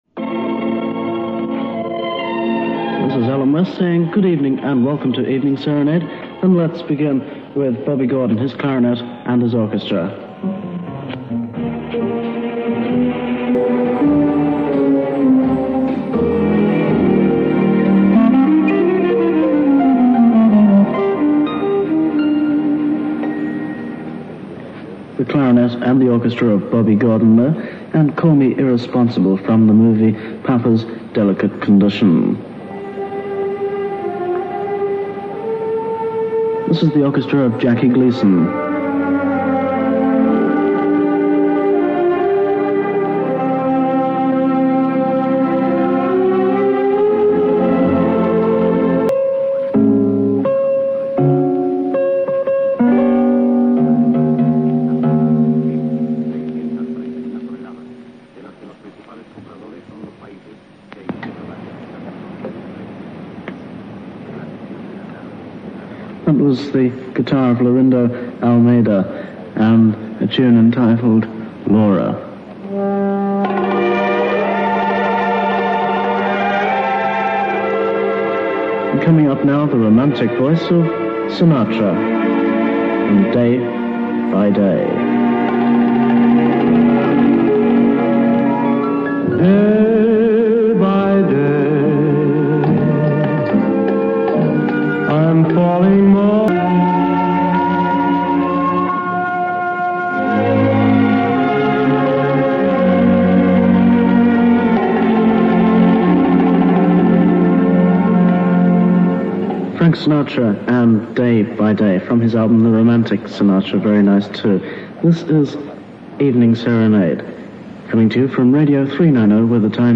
The unique selling point of Radio 390 was its substantially different format - ‘sweet music’ (what     today may be termed ‘smooth’ or ‘easy listening’).